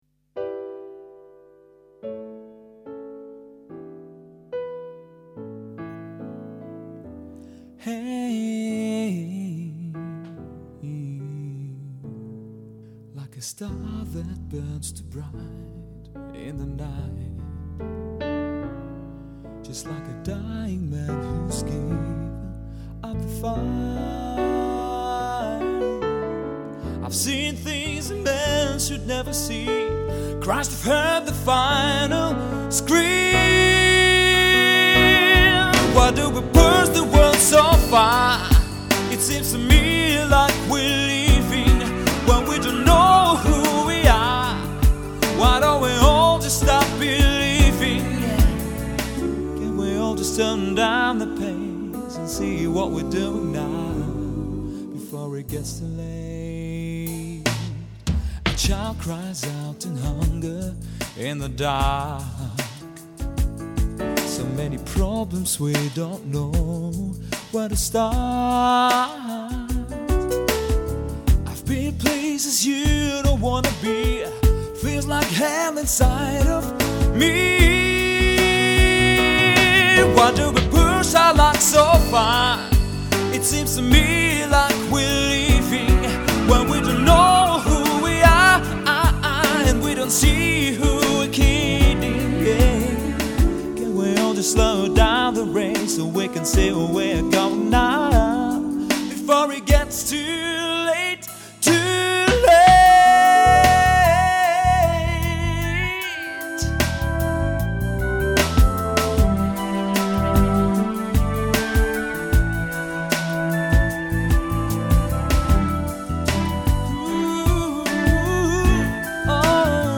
On the journey to my musician friend’s house, I wrote a song about the impact it had on me. And we recorded it that same day.